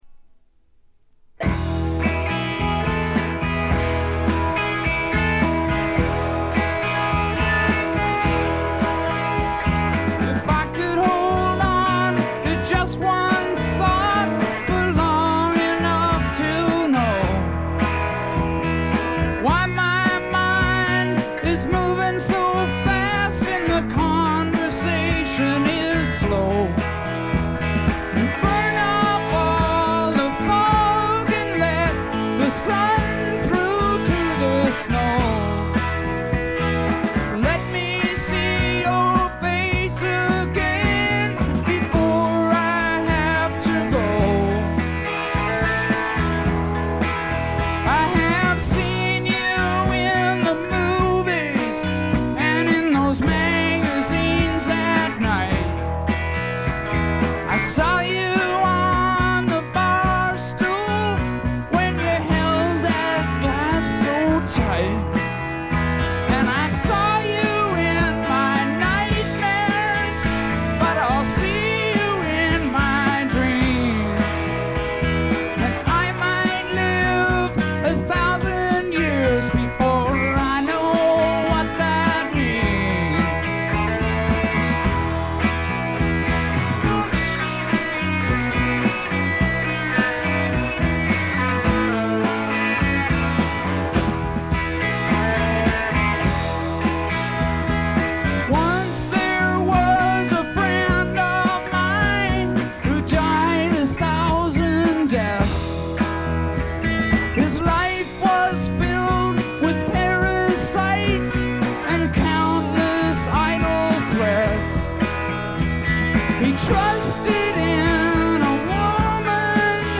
*** LP ： USA 1975
ごくわずかなノイズ感のみ。
試聴曲は現品からの取り込み音源です。